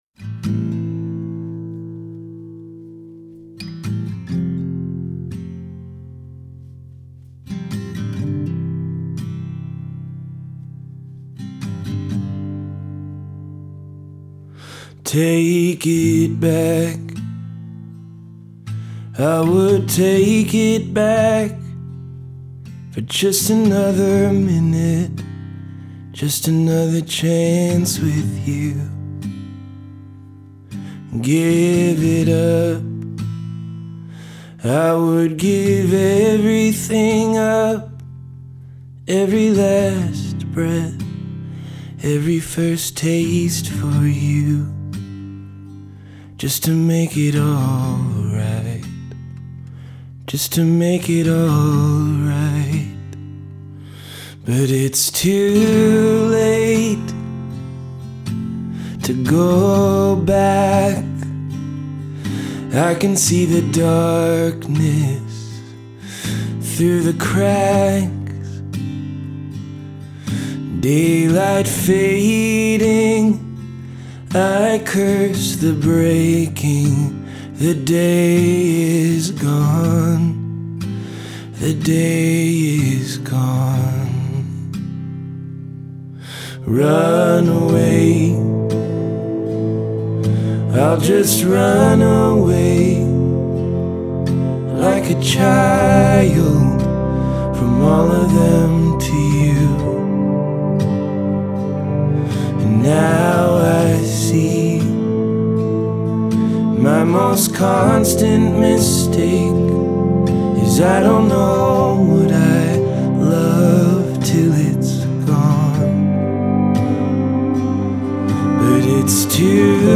Indie Rock Folk rock